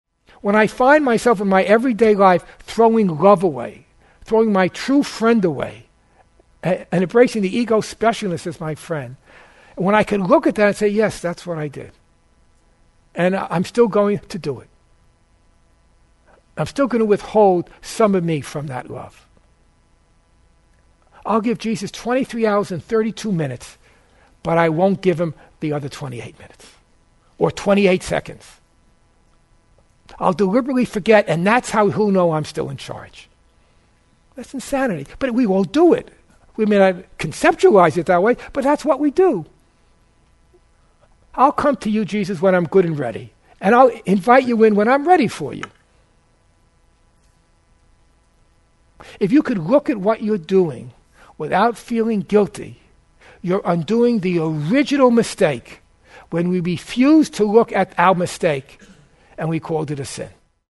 The workshop focuses on our mind’s two friends: the ego and the Holy Spirit.